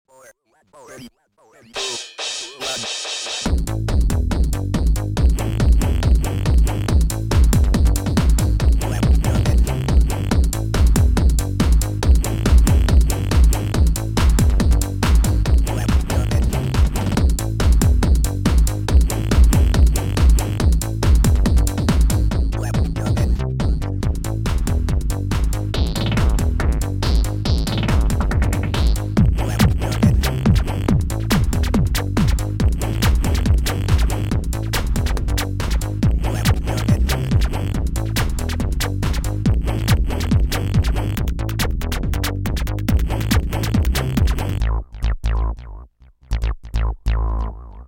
• Tempo: 140 BPM
• Key: A minor.